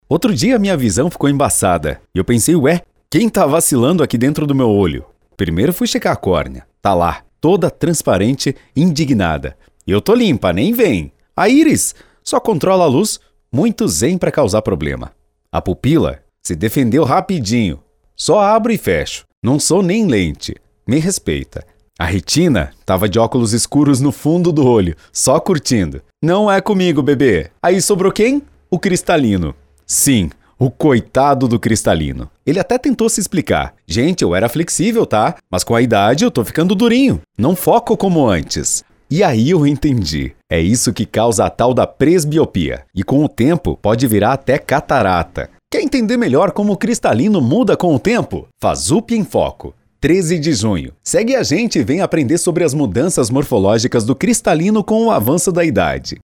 Quer interpretação com naturalidade ?